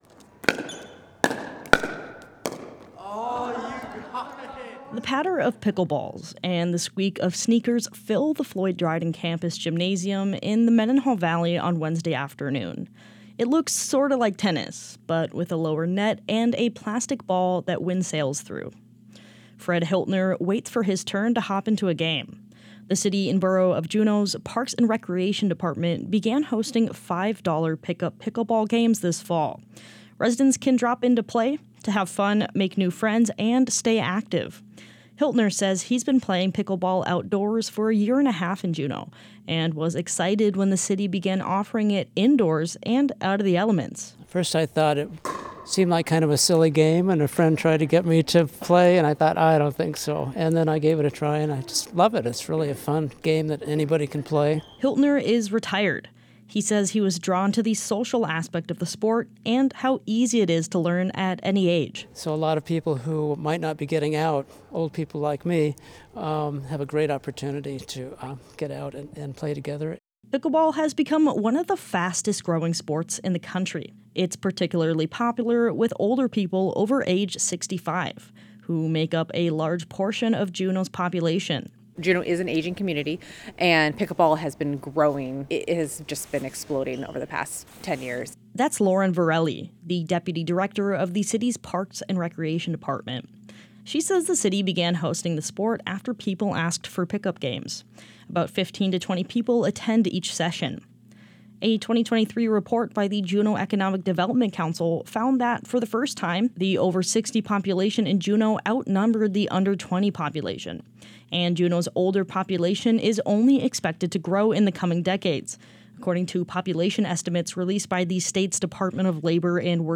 The patter of pickleballs and squeak of sneakers filled the Floyd Dryden campus gymnasium in the Mendenhall Valley last Wednesday afternoon.
01pickleball_.wav